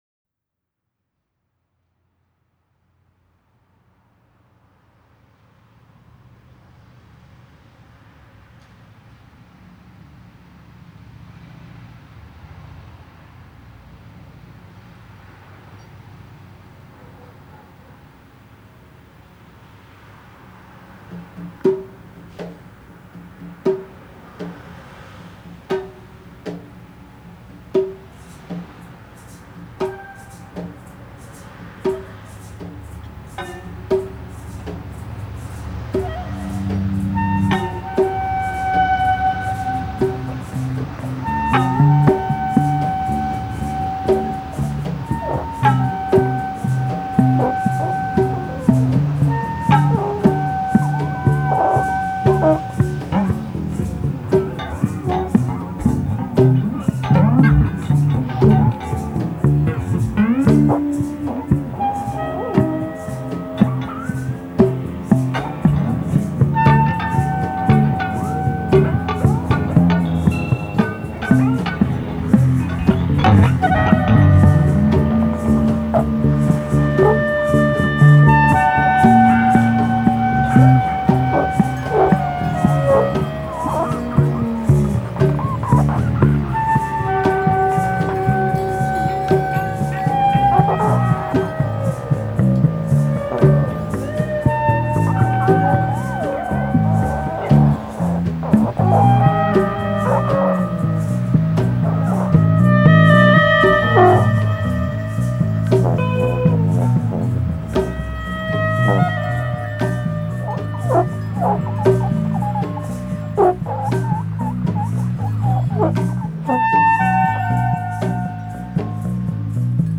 Recorded in the Living Room,
Cocktail Jazz